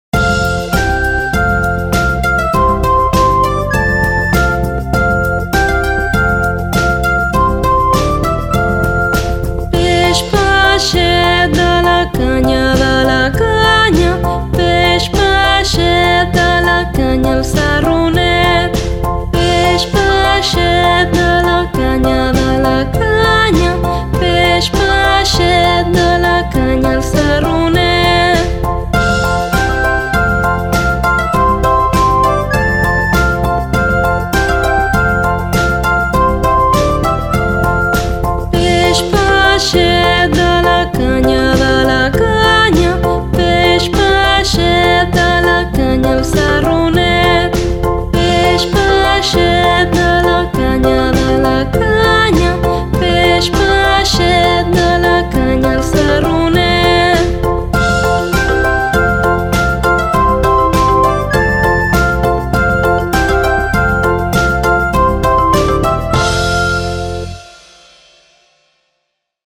peix-peixet-de-la-canya-cancons-infantils-en-catala-the-fish-rhyme-in-catalan.mp3